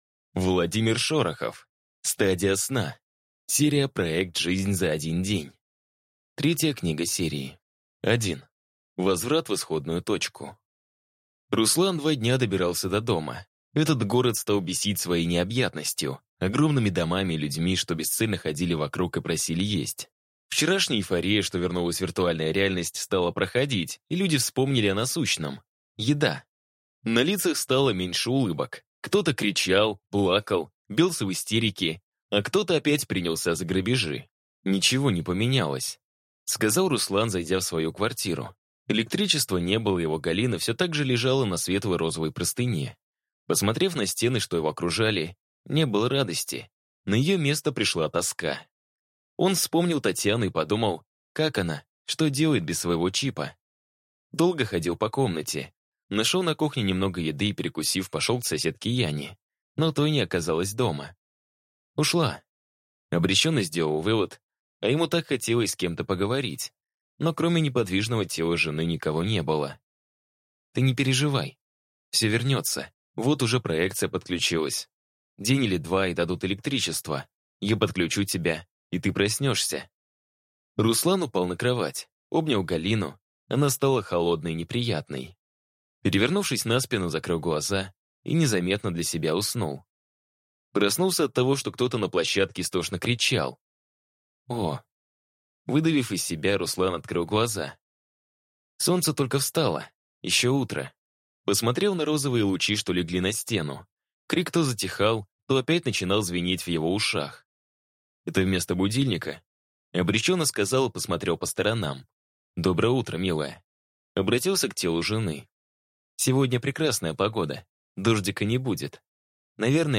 Аудиокнига Стадия сна | Библиотека аудиокниг
Прослушать и бесплатно скачать фрагмент аудиокниги